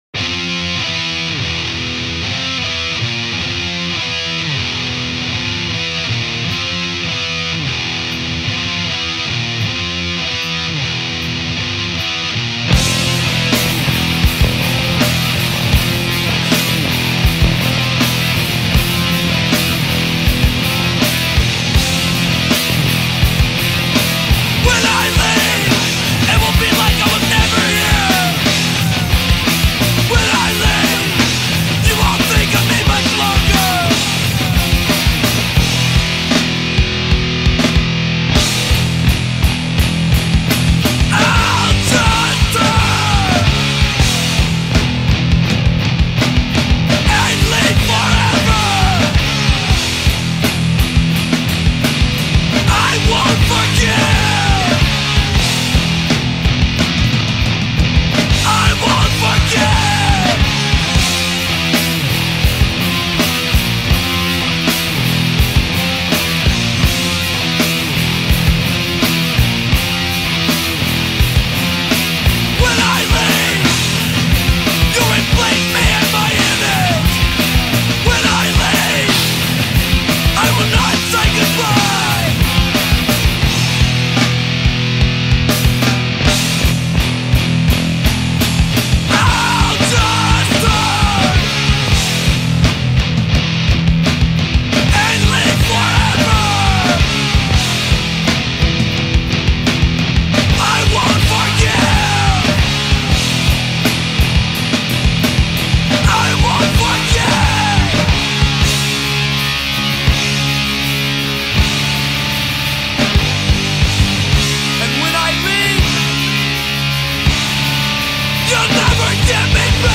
90's Straight edge hardcore, Metalcore from Seattle